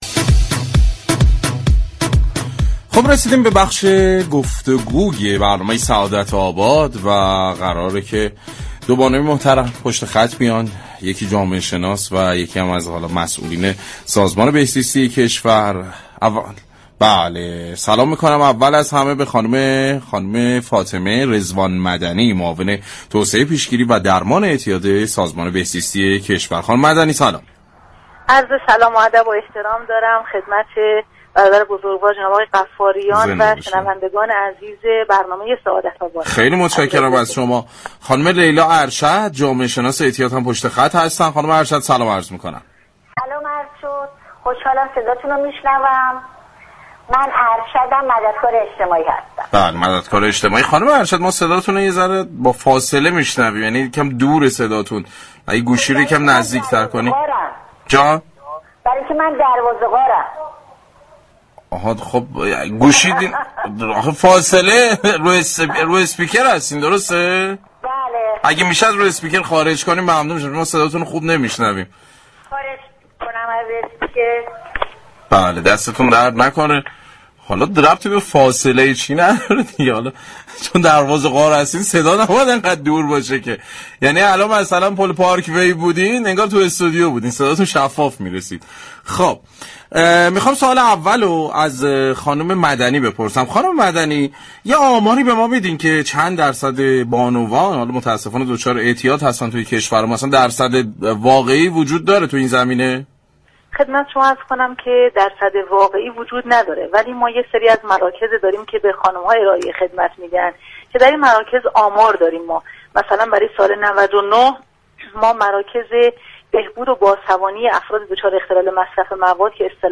در گفتگو با برنامه سعادت آباد بر ارائه راهكارهای پیشگیری از اعتیاد و خدمات بیشتر برای زنان تاكید كردند.